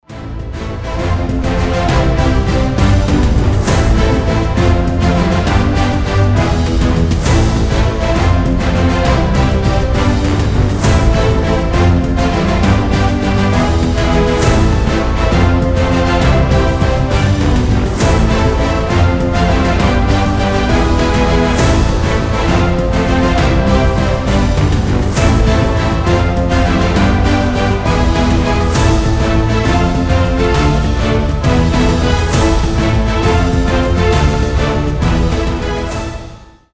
Рингтоны без слов
Эпичные
Инструментальные , OST